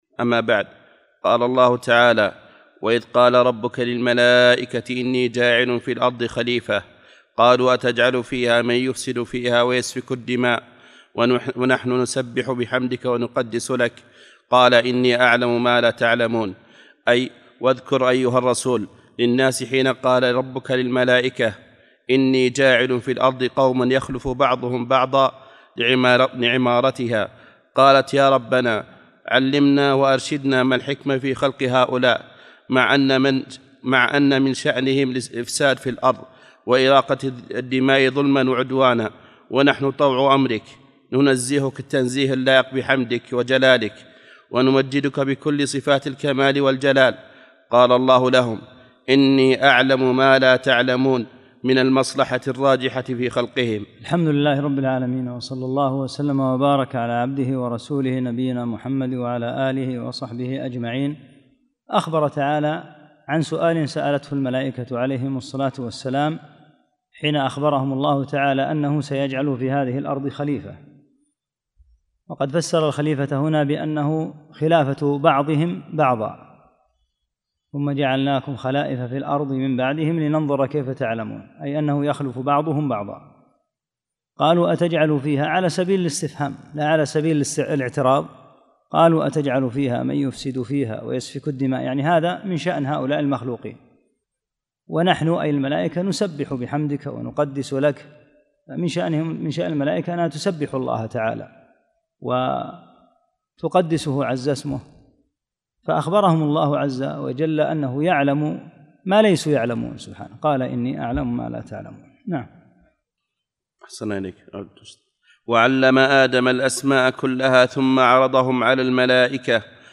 5- الدرس الخامس